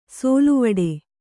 ♪ sōluvaḍe